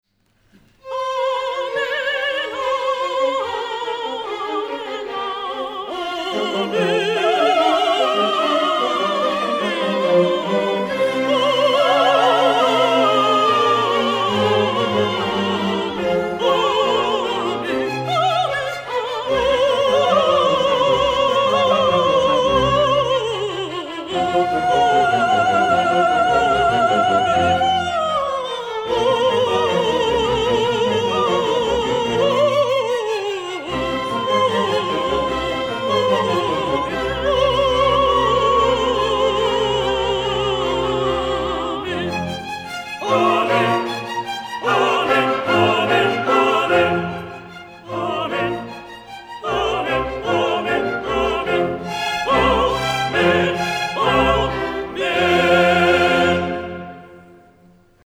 coll'aggiunta de' stromenti a fiato